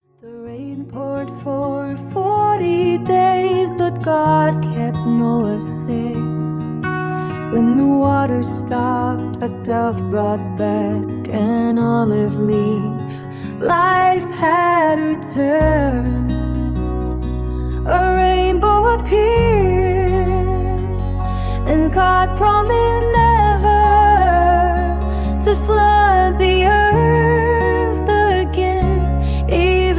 Ai Generated Noah Ark Bible Stories